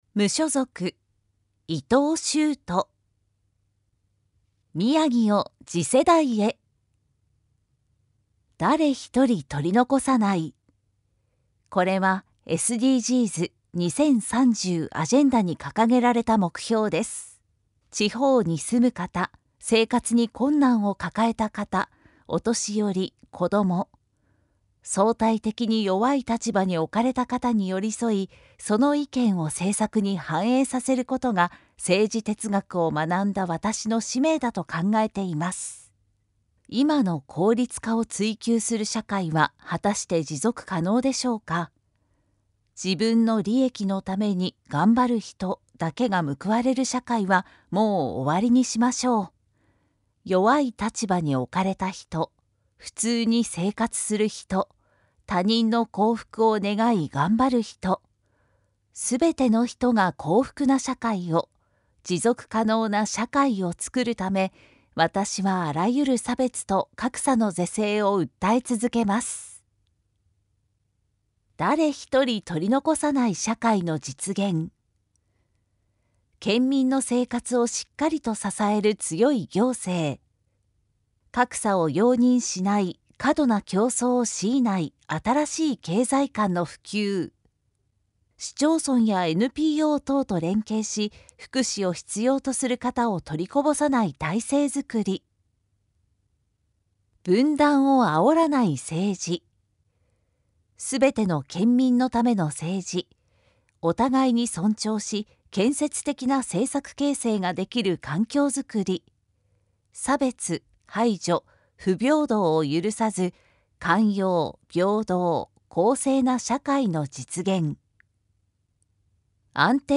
宮城県知事選挙候補者情報（選挙公報）（音声読み上げ用）